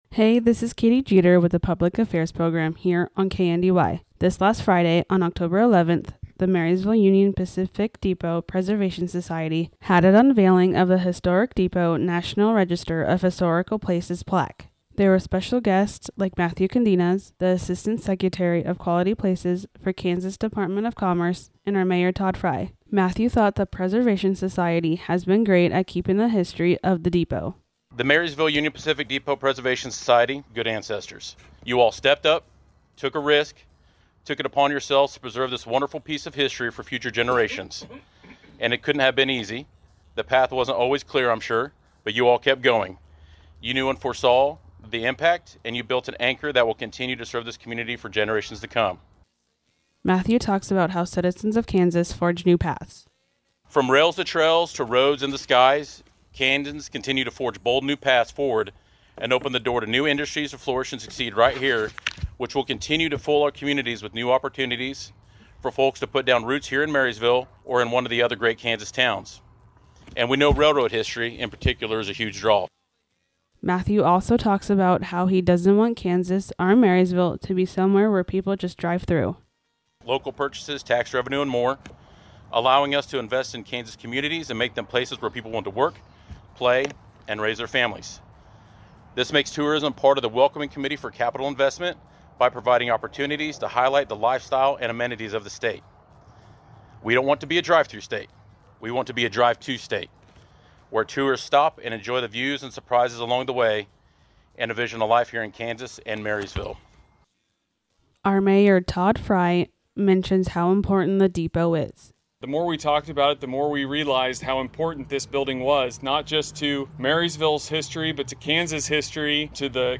The group unveiled a National Register of Historic Places plaque honoring their historic depot. The event featured remarks from Matthew Godinez, Assistant Secretary of Quality Places for the Kansas Department of Commerce, Marysville Mayor Todd Frye